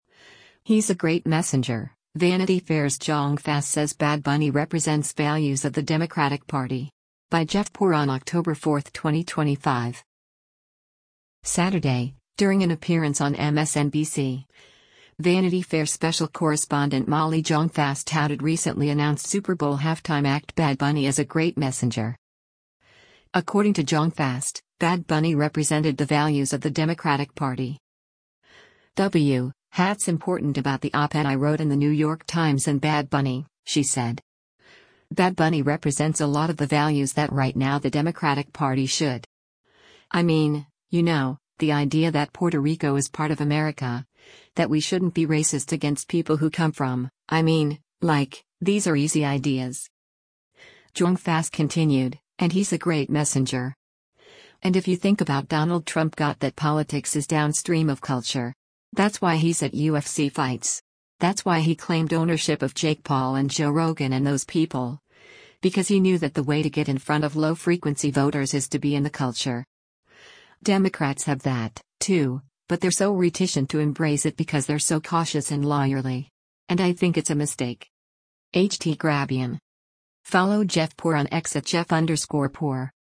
Saturday, during an appearance on MSNBC, Vanity Fair special correspondent Molly Jong-Fast touted recently announced Super Bowl halftime act Bad Bunny as a “great messenger.”